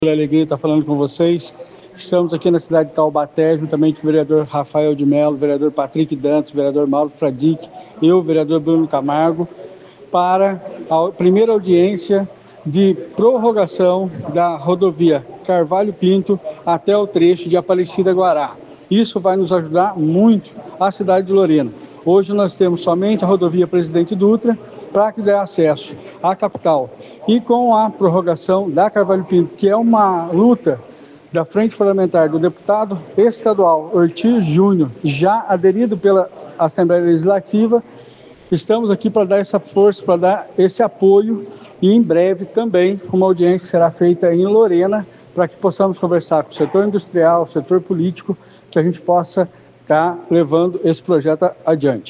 Vereador Bruno Camargo (PSD)